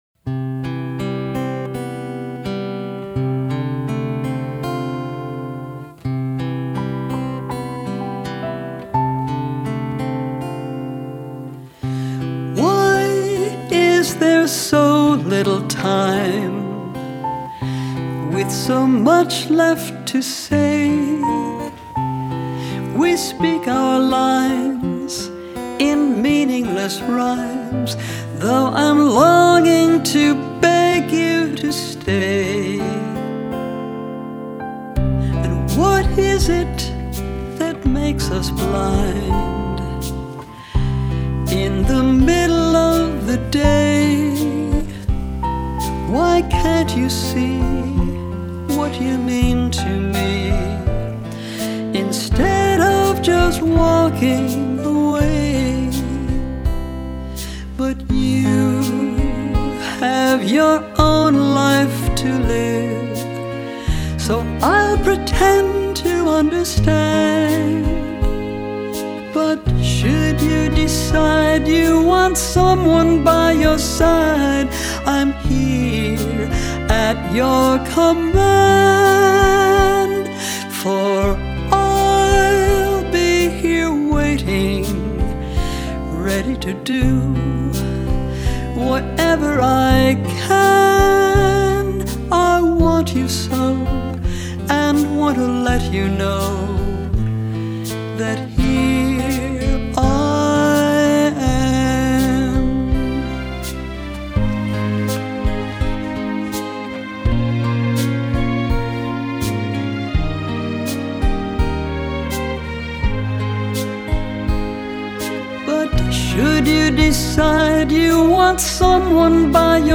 Only recently has this one been ‘orchestrated’.